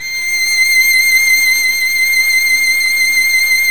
Index of /90_sSampleCDs/Roland - String Master Series/STR_Violin 2&3vb/STR_Vln2 mf vb